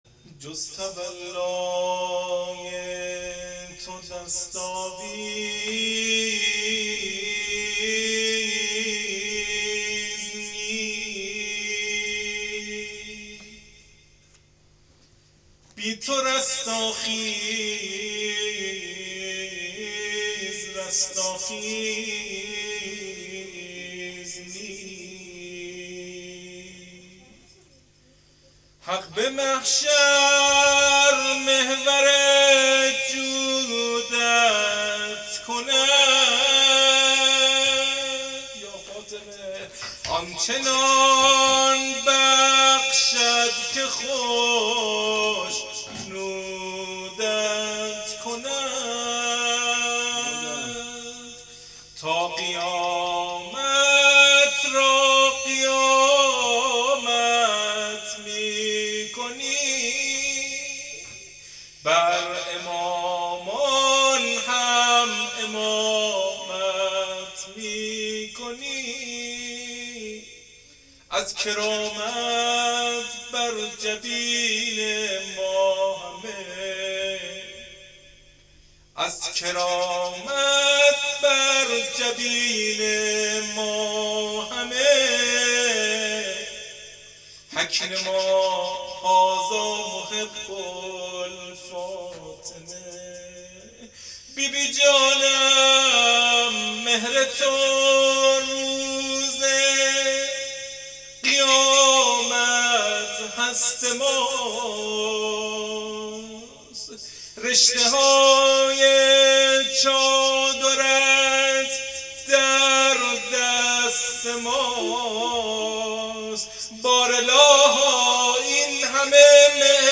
روضه شهادت حضرت زهرا زبان حضرت زینب